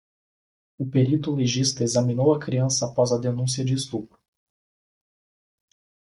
Pronounced as (IPA) /peˈɾi.tu/